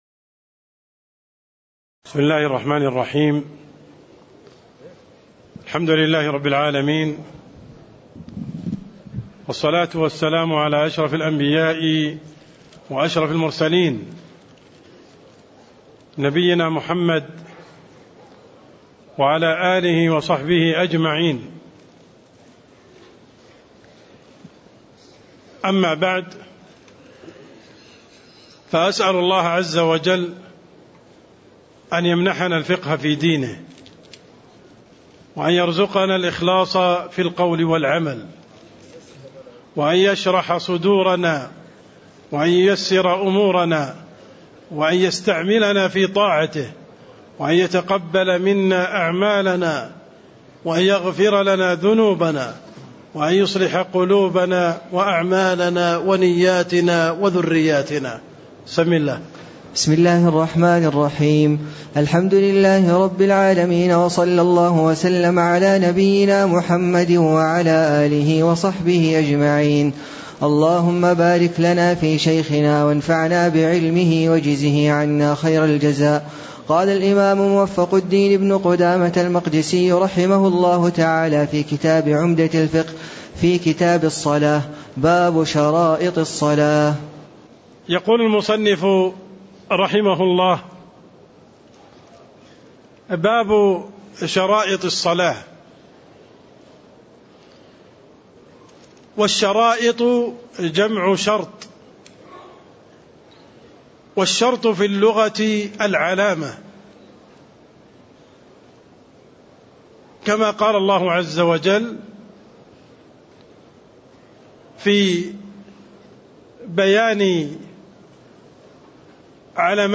تاريخ النشر ٢١ ذو القعدة ١٤٣٥ هـ المكان: المسجد النبوي الشيخ: عبدالرحمن السند عبدالرحمن السند باب شروط الصلاة (04)